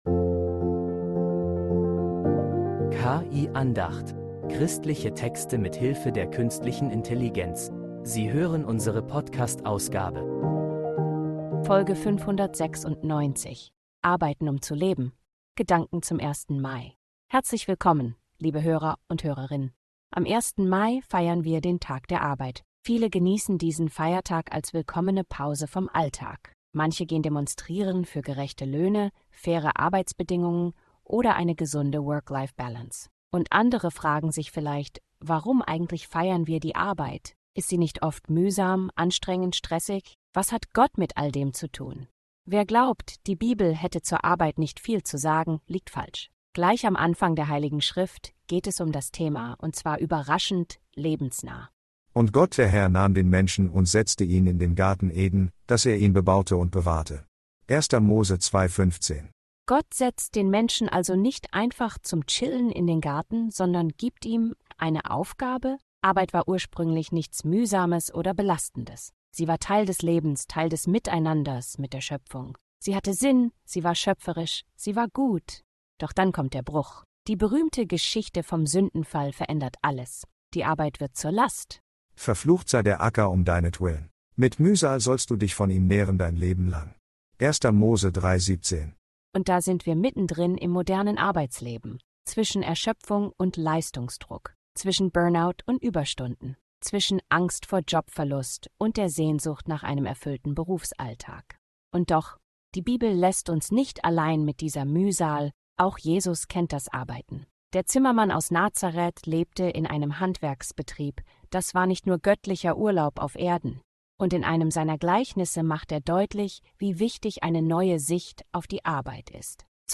Diese Andacht schaut in die Bibel – und entdeckt Überraschendes.